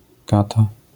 wymowa:
IPA[ˈkata], AS[kata]